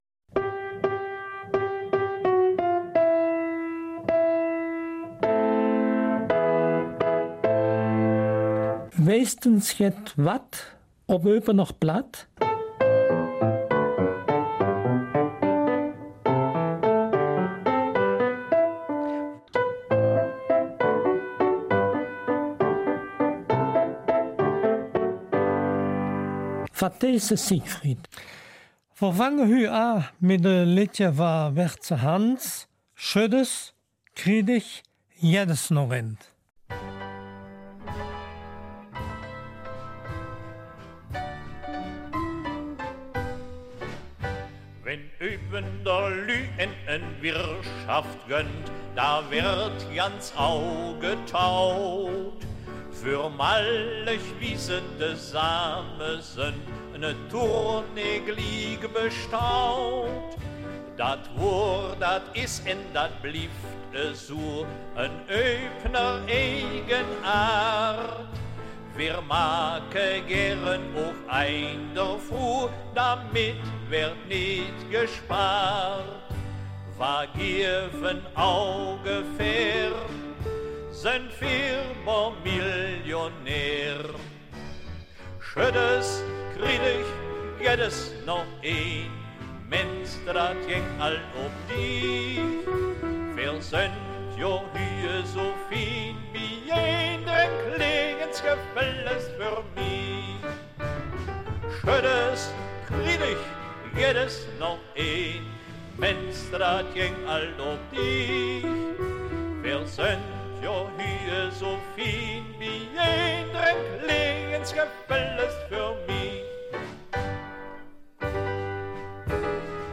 Eupener Mundart - 13.